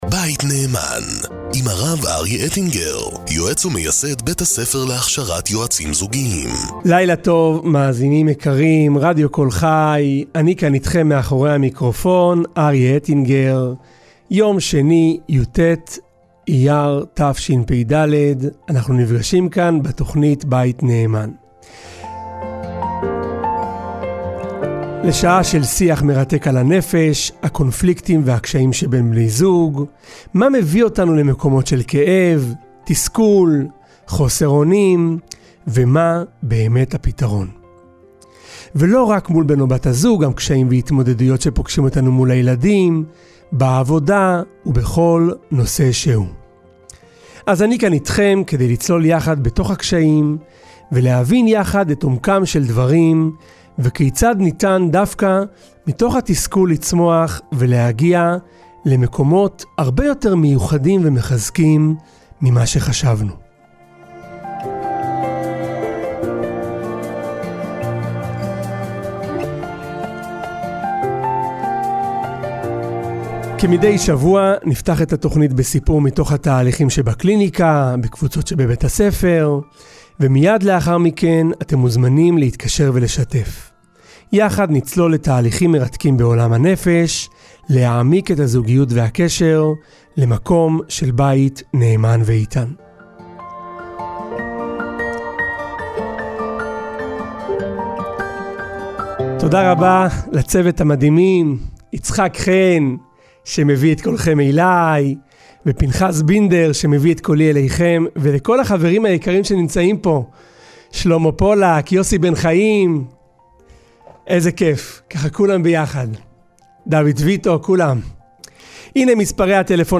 הדיאלוג הולך ומתלהט כשהטריגר הפעם, הסכמת האישה לילד בן התשע ללכת ולאסוף קרשים לל"ג בעומר ביער בשעה מאוחרת בלילה.